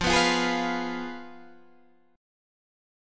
F Diminished